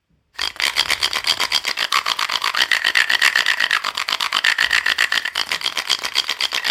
アホコ　太棒タイプ　 アフリカ民族楽器　 （p040-52）
これは棒が太く刻み目のピッチが広く、また刻みのエッジがソフトなためより柔らかく低い音を出します。
丸い木の実を上下にスライドさせて音を出します。
１　丸い実を上下にスライトさせる。乾いたガラガラ音。
２　１の動作をしながら平らなジュジュの実を棒の柄に触れさせる。ガラガラ音がガーガーと深い音に変化する
３　2の動作をしながらジュジュの実の穴を親指で塞ぐ。（塞ぐ幅で調音が可能）ガーガー音がゴーゴー音に変化する
この楽器のサンプル音